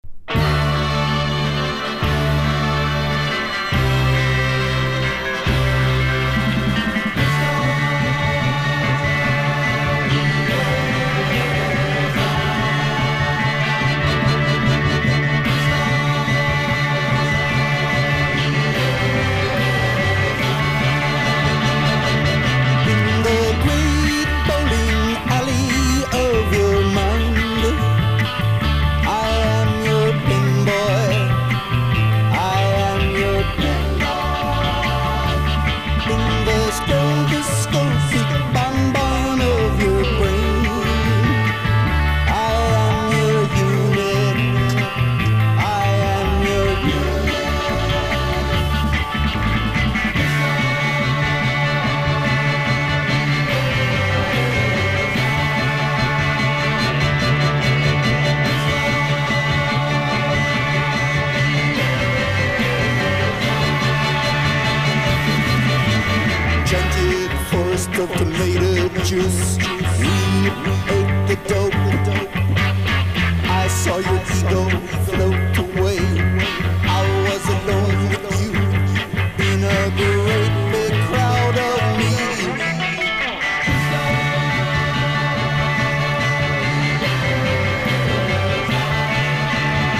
1. 60'S ROCK >
PSYCHEDELIC / JAZZ / PROGRESSIVE
ブラスが入ったメロウでソフトなサイケロック・ナンバー